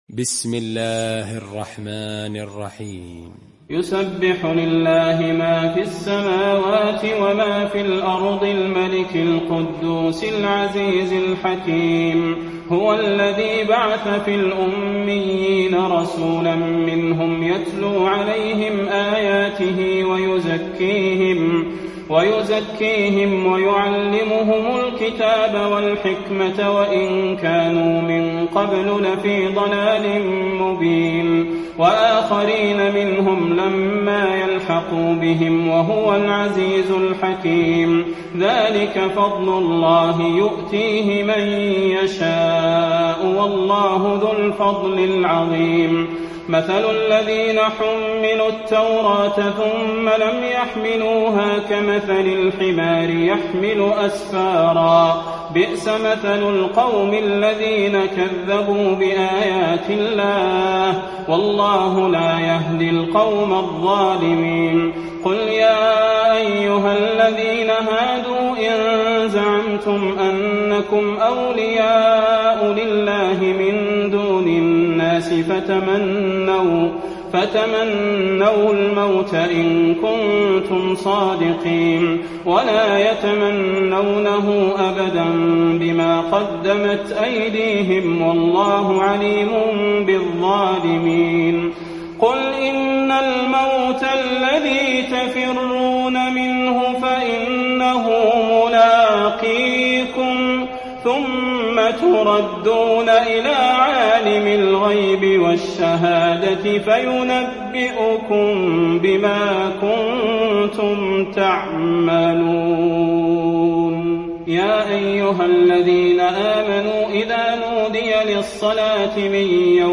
المكان: المسجد النبوي الجمعة